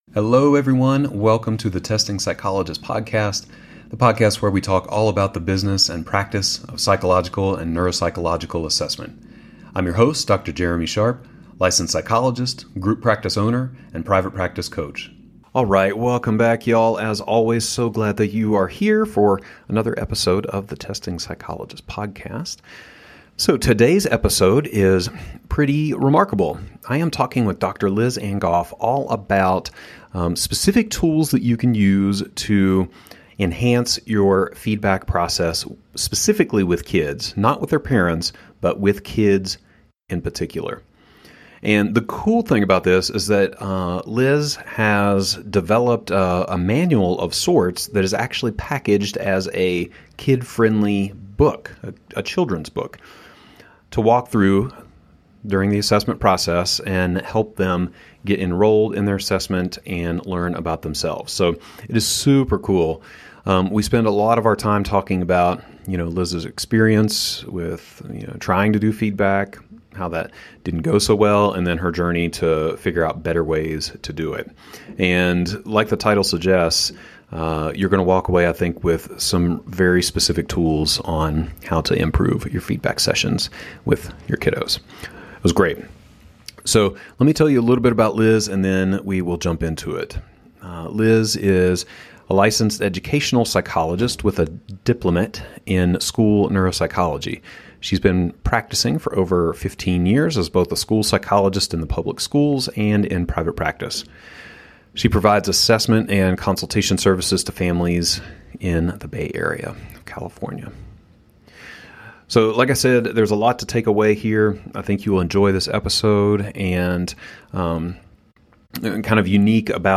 This interview was published in January of 2021 Format: Asynchronous, distance learning.